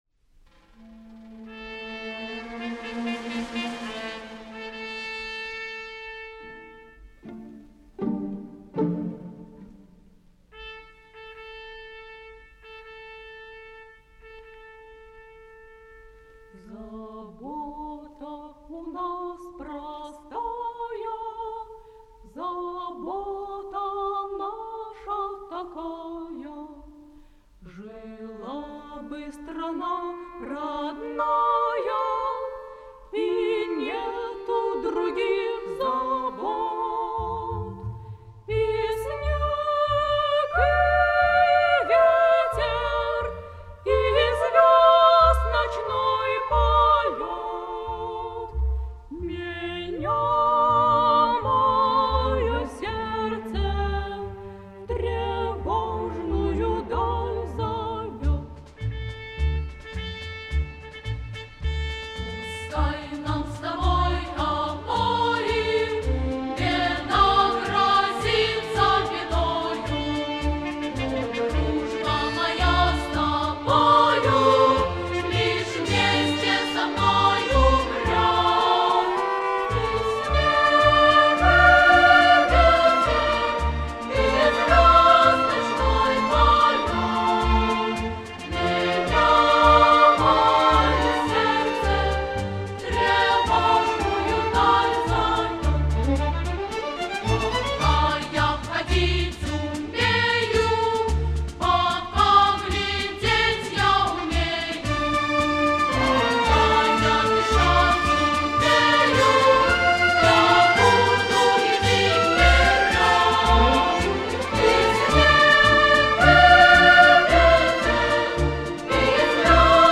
солистки